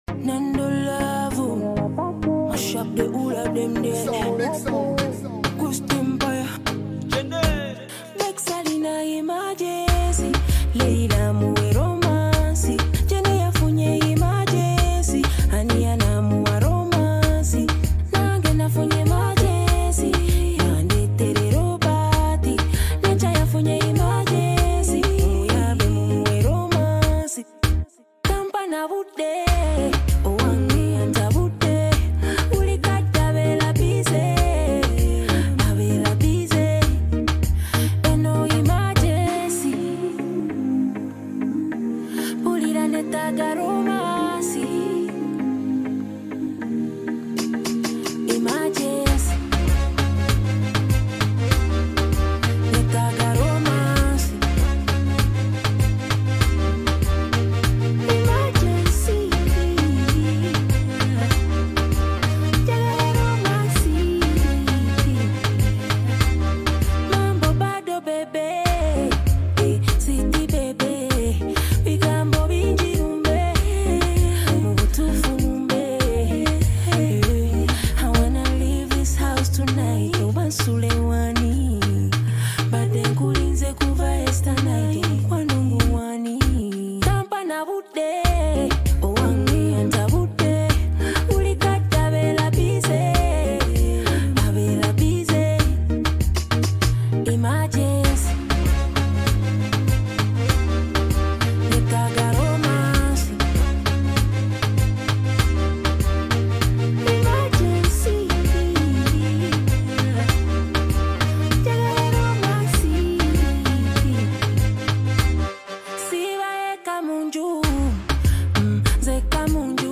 exciting new Afrobeat single
With bold lyrics and an upbeat rhythm